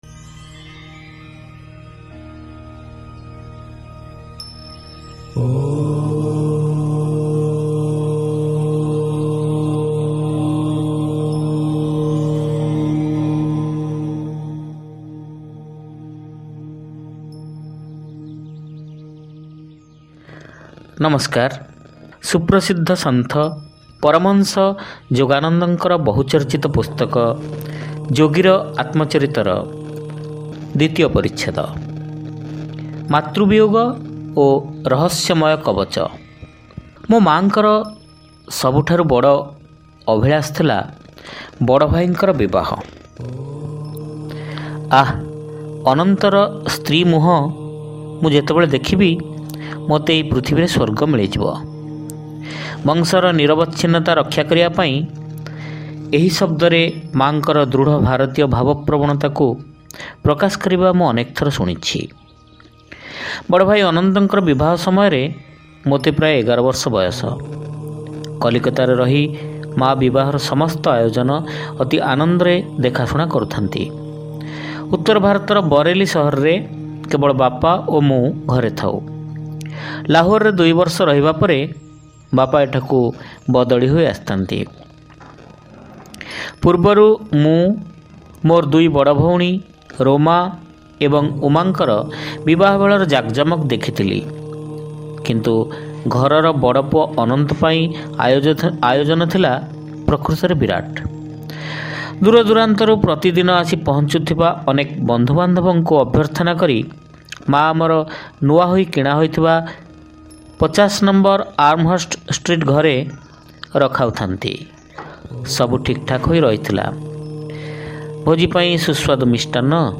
ଶ୍ରାବ୍ୟ ଗଳ୍ପ : ମାତୃବିୟୋଗ ଓ ରହସ୍ୟମୟ କବଚ-ଯୋଗୀର ଆତ୍ମଚରିତ